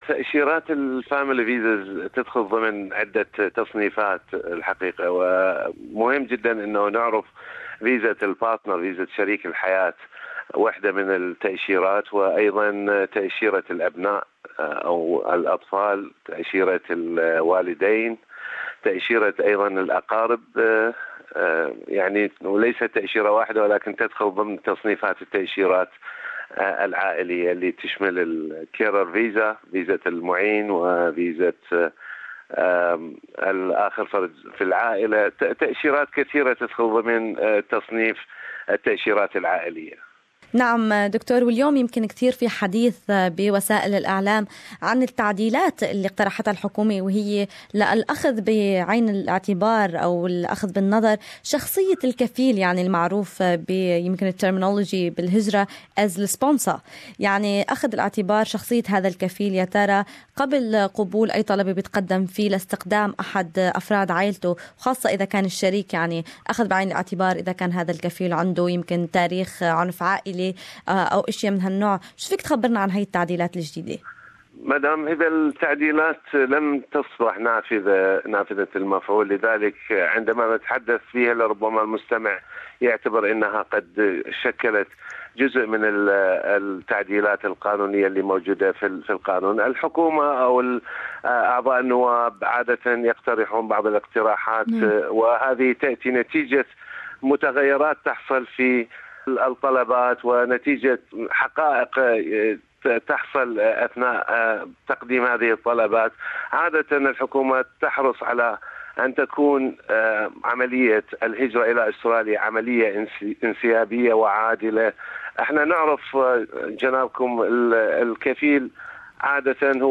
لقاءً مباشرا